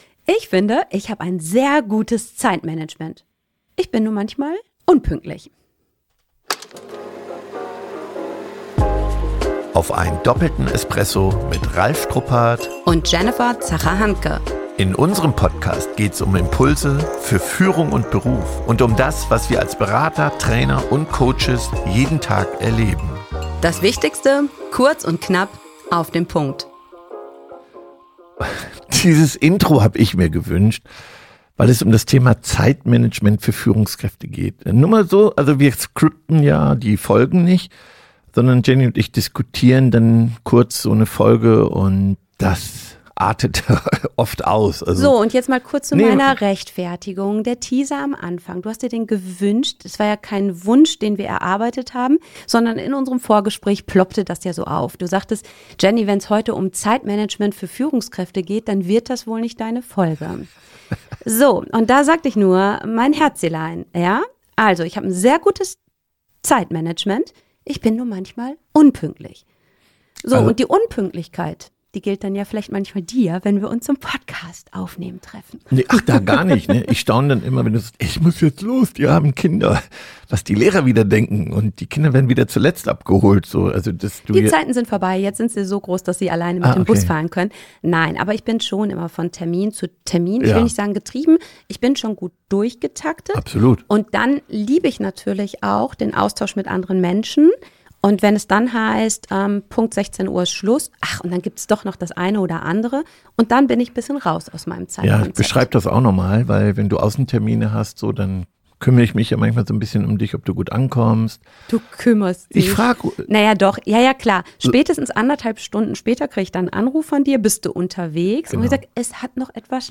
Begleite uns auf einer Reise durch die Themen Führung, Kommunikation, Rekrutierung und Motivation. Erlebe dabei nahbare Dialoge, authentische Solofolgen und inspirierende Interviews.